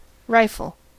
Ääntäminen
US : IPA : [ˈrɑɪ.fəl]